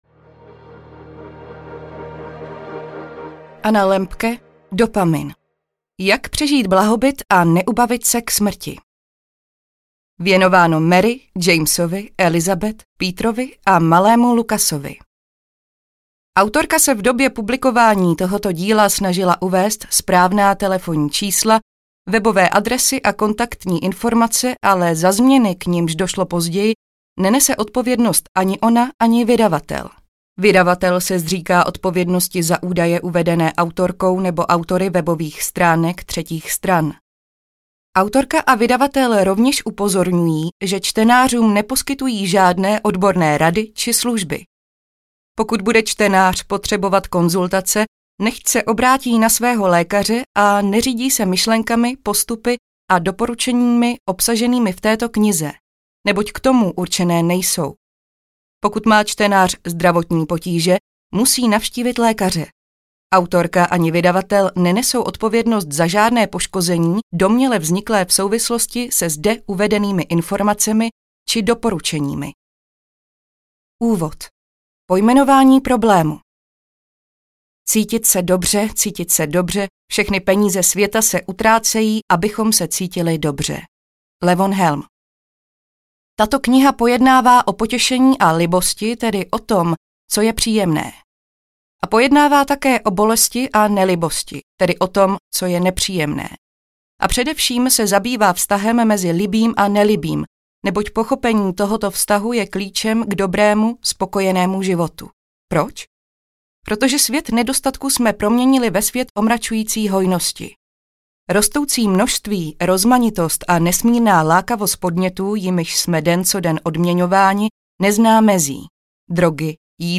Dopamin audiokniha
Ukázka z knihy